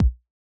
ITA Kick.wav